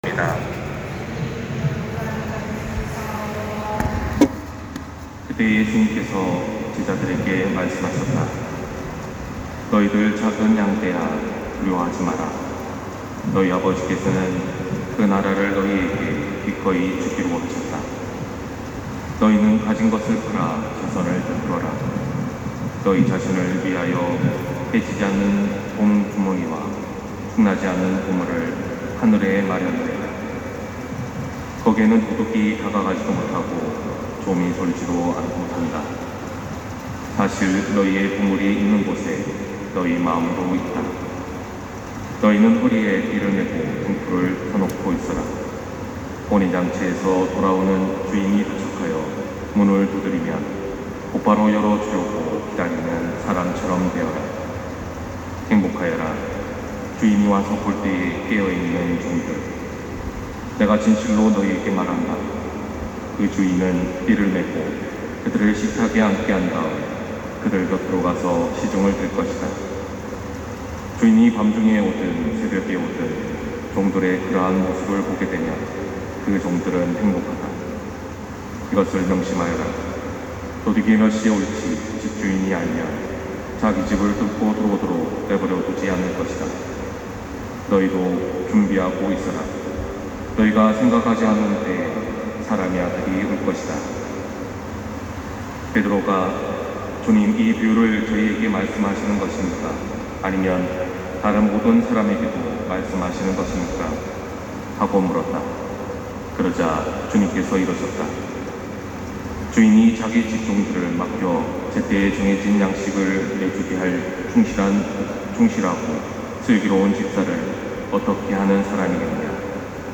250809 신부님 강론말씀